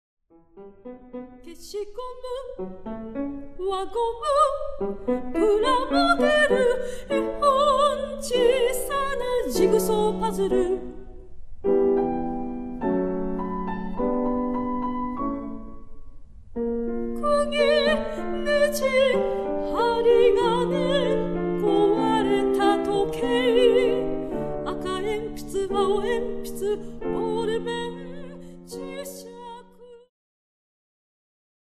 ジャンル POPS系
癒し系
歌・ピアノ
シタール